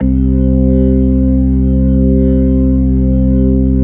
Index of /90_sSampleCDs/AKAI S-Series CD-ROM Sound Library VOL-8/SET#5 ORGAN
HAMMOND   6.wav